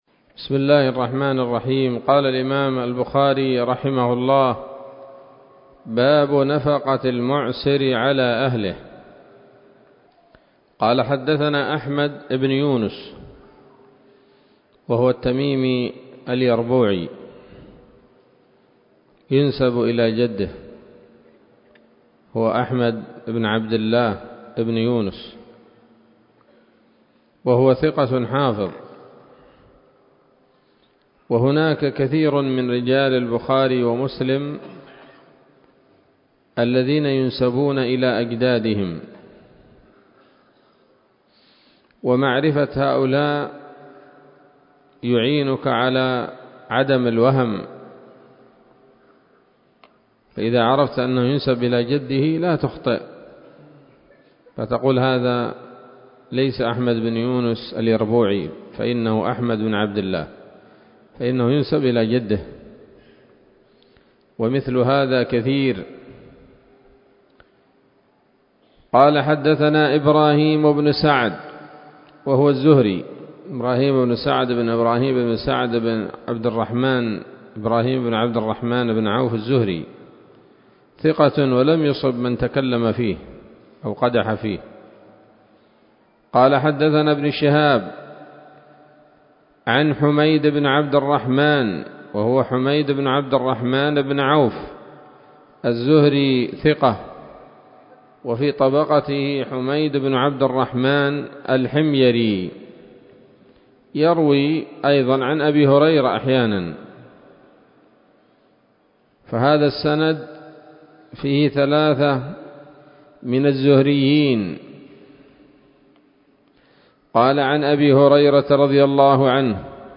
الدرس العاشر من كتاب النفقات من صحيح الإمام البخاري